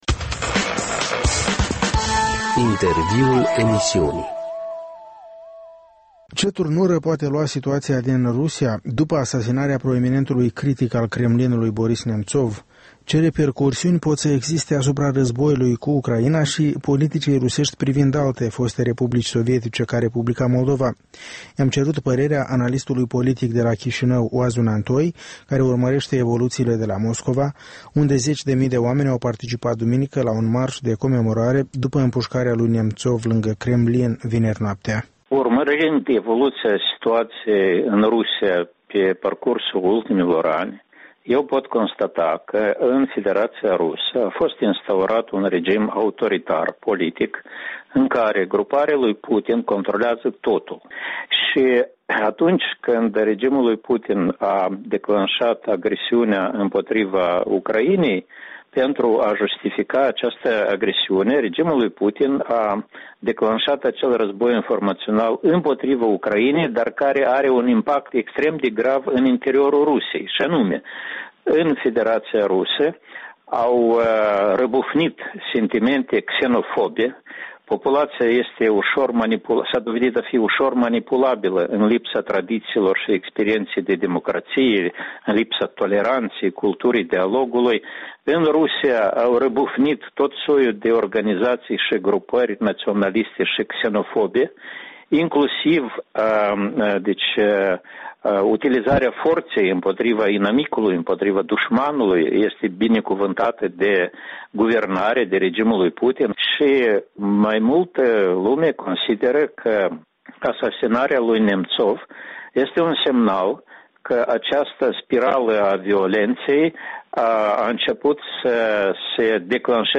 Interviul dimineții: cu Oazu Nantoi